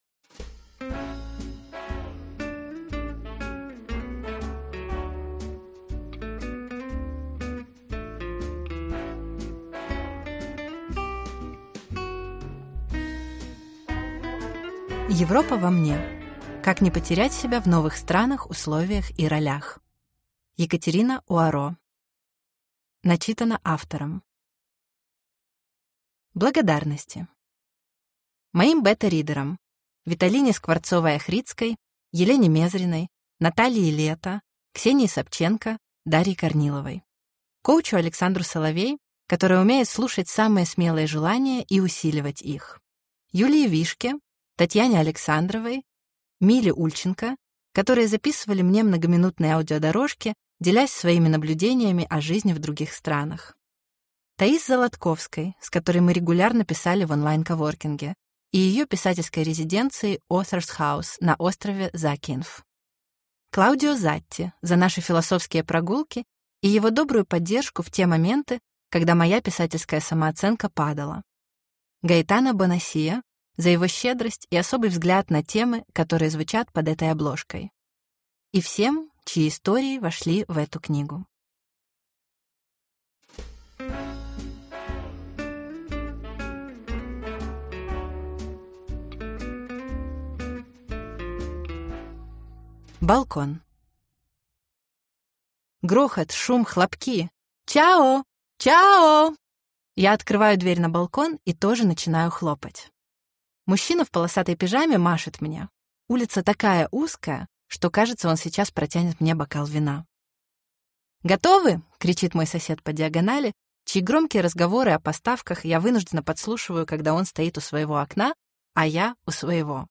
Аудиокнига Европа во мне. Как не потерять себя в новых странах, условиях и ролях | Библиотека аудиокниг